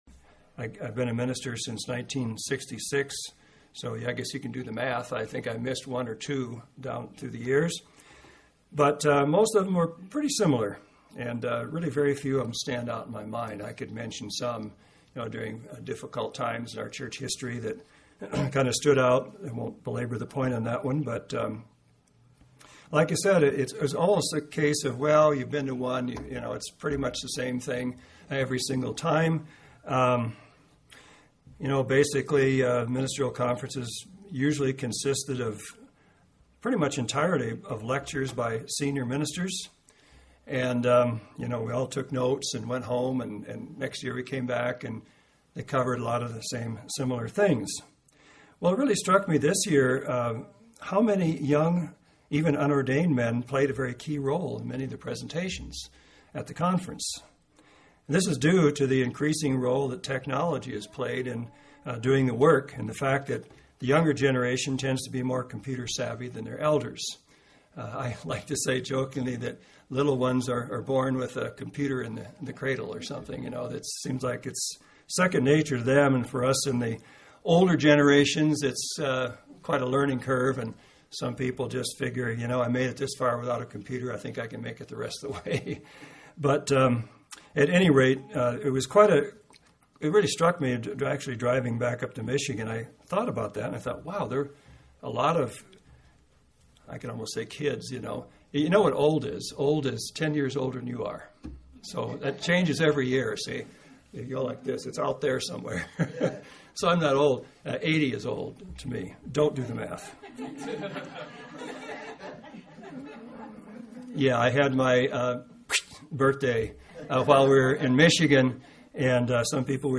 Social Media and Personal Evangelism Bend 5-27-11